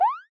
hop.ogg